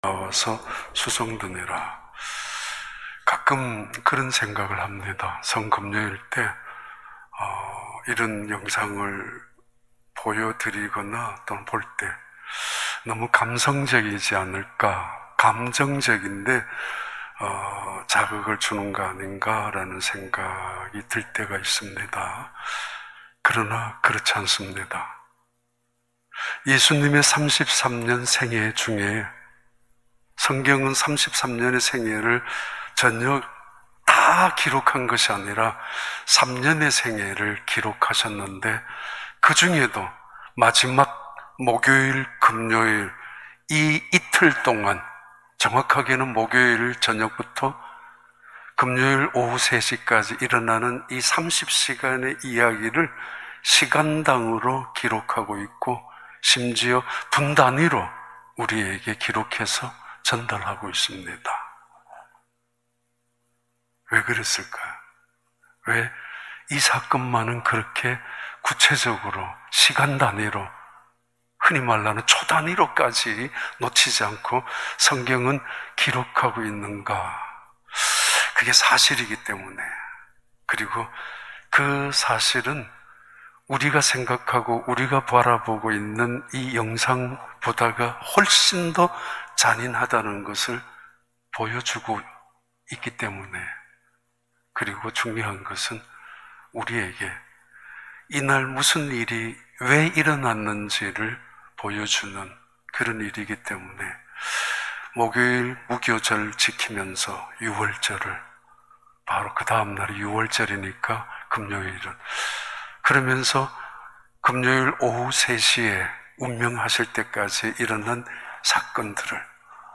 2021년 4월 2일 성금요기도회